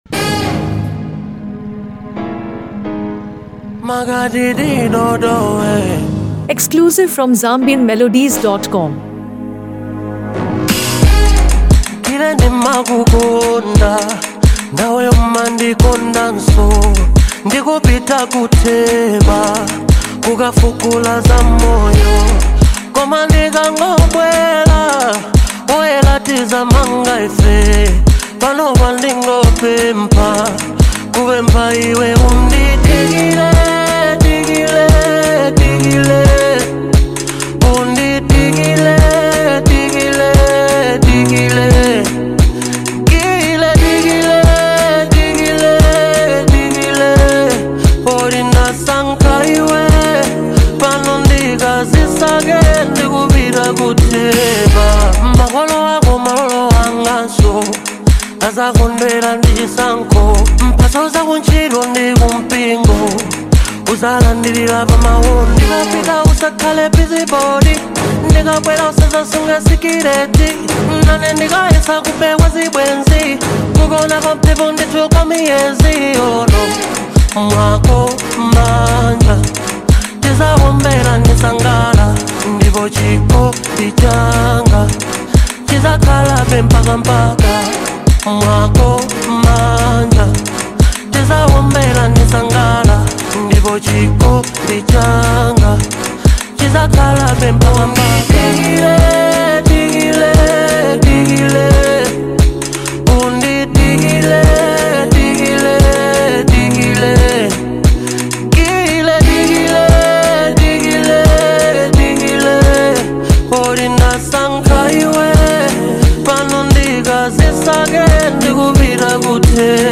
a deeply emotional and soulful masterpiece
Known for his heartfelt lyrics and smooth vocal delivery